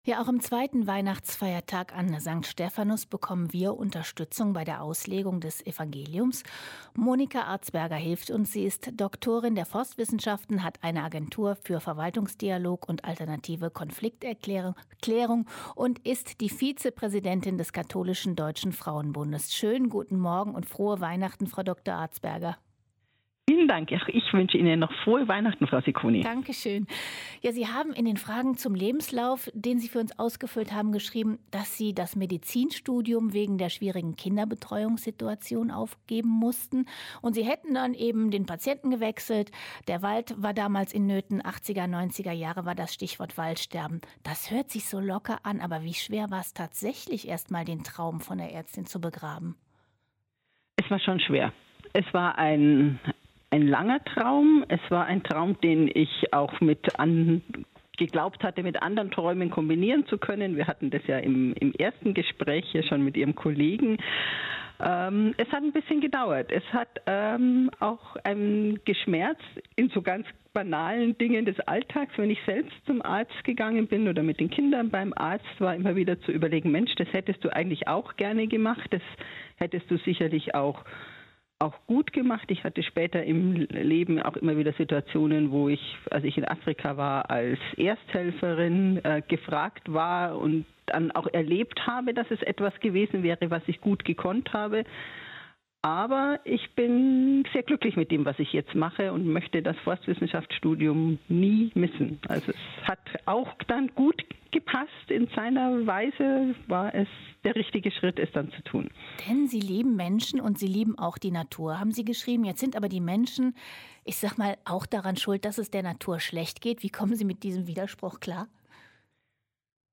Mt 10,17-22 - Gespräch